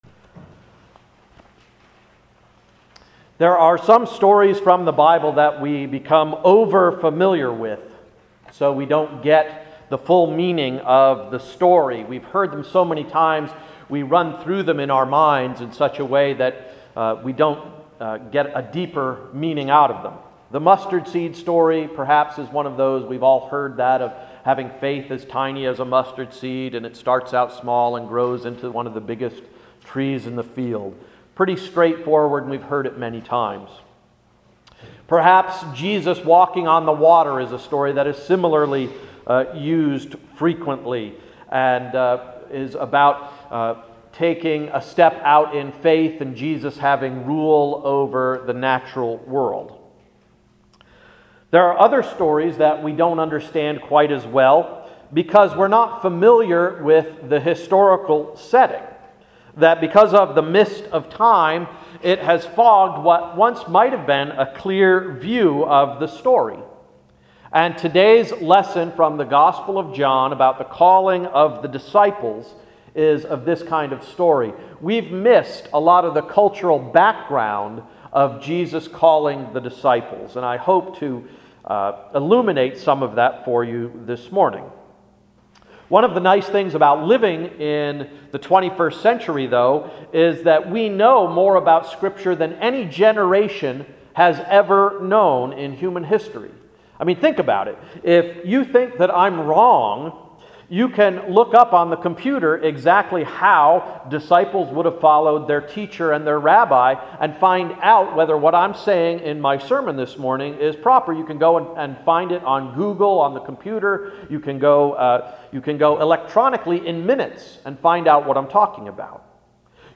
Here is this Sunday’s sermon: The Disciples’ Diary